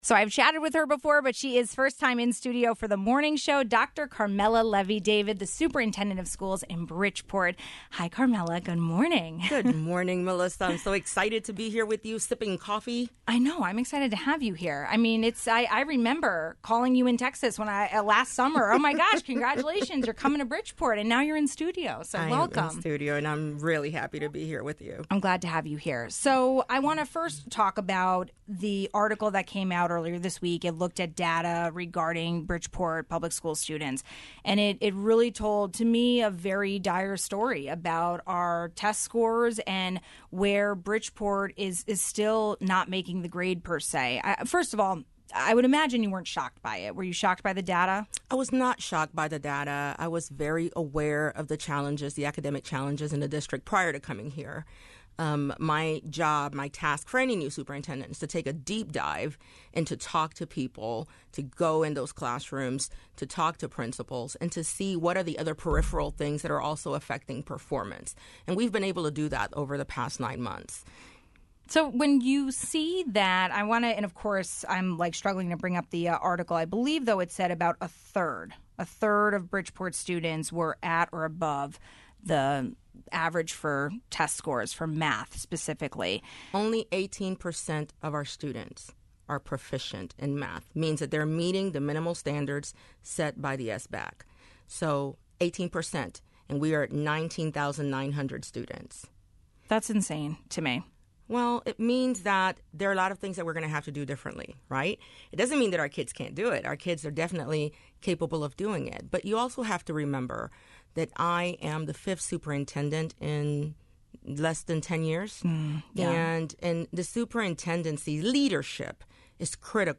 We had Dr. Carmela Levy-David in studio. She is the superintendent of schools in Bridgeport and outlined ongoing issues starting with consistent leadership.